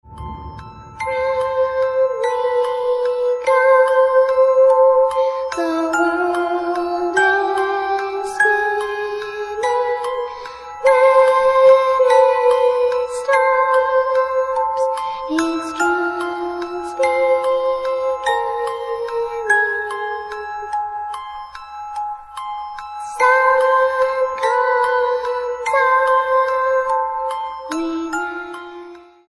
• Качество: 256, Stereo
пугающие
страшные
мистические
зловещие